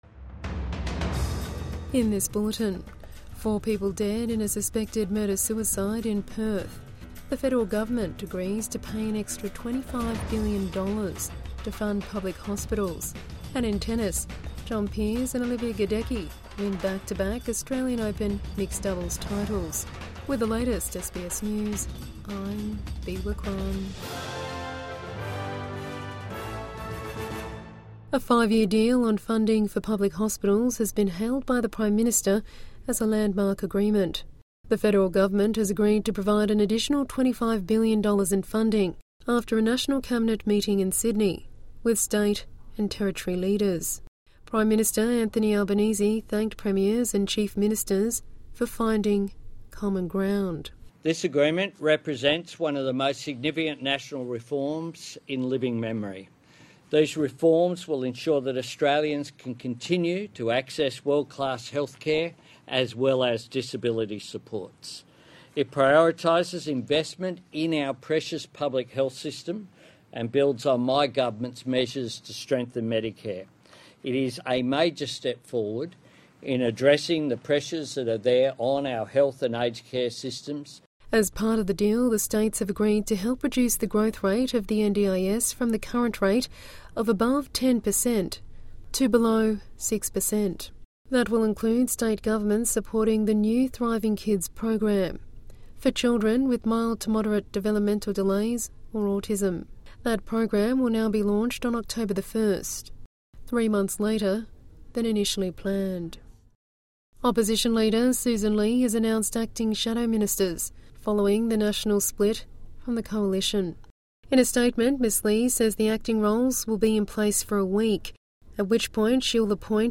Four people dead in suspected murder-suicide in Perth | Evening News Bulletin 30 January 2026